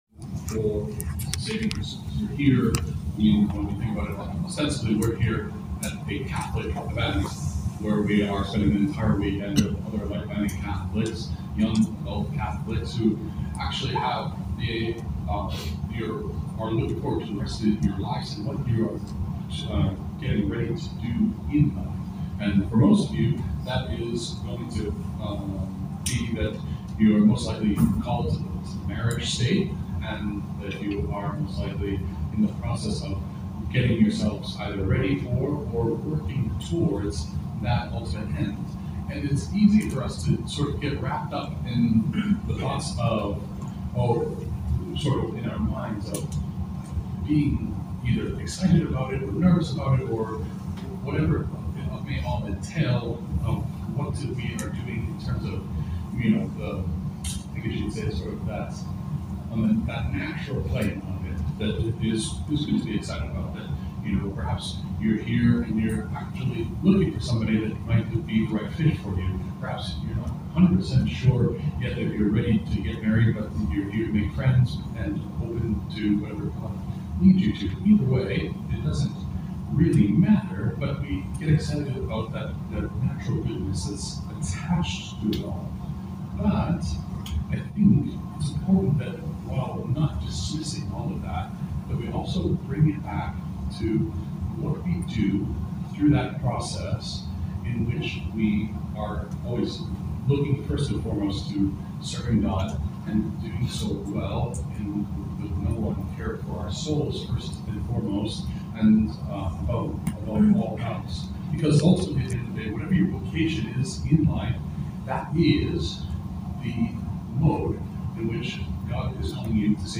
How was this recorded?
93 souls from the far reaches of North America and beyond converged upon our small slice of suburban Cincinnati this July for the 8th annual Young Adult Get-together.